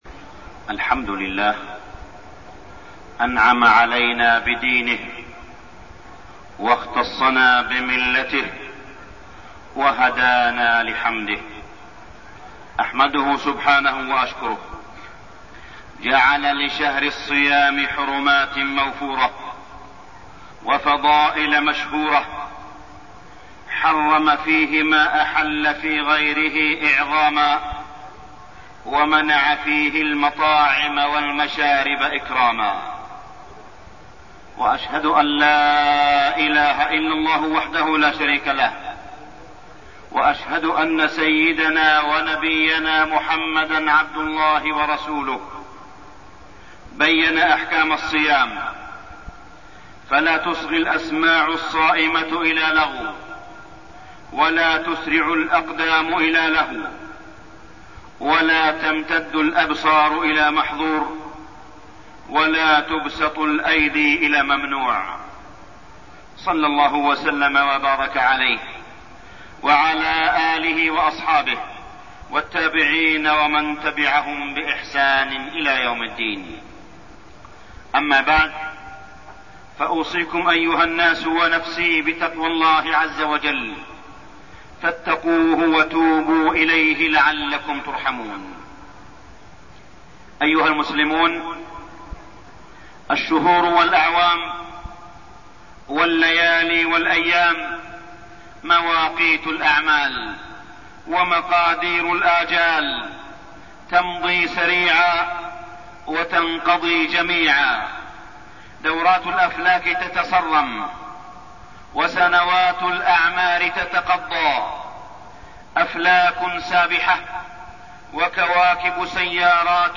تاريخ النشر ٢٧ رمضان ١٤١٦ هـ المكان: المسجد الحرام الشيخ: معالي الشيخ أ.د. صالح بن عبدالله بن حميد معالي الشيخ أ.د. صالح بن عبدالله بن حميد التقصير والفتور بعد رمضان